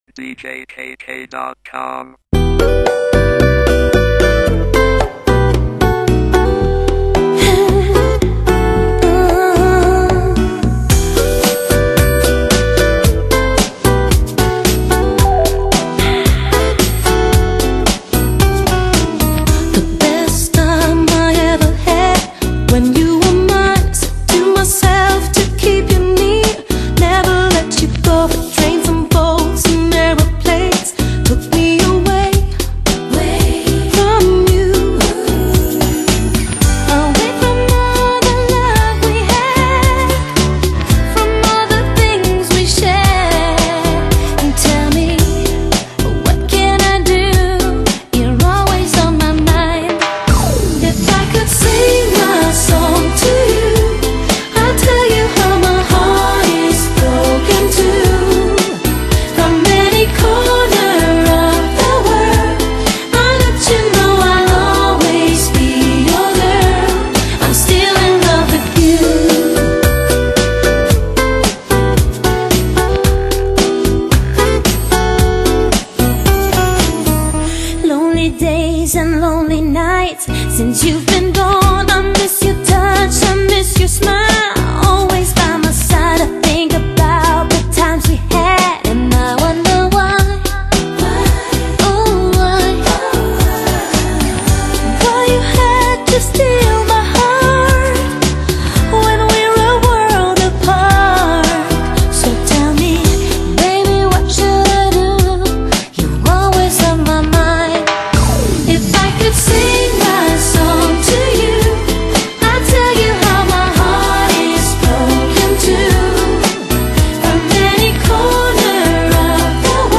[10/4/2010]【周末舞曲】酒吧暖场性感RNB舞曲 激动社区，陪你一起慢慢变老！